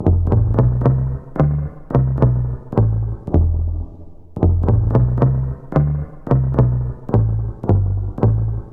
描述：雷鬼低音吉他
Tag: 110 bpm Reggae Loops Bass Guitar Loops 1.47 MB wav Key : Unknown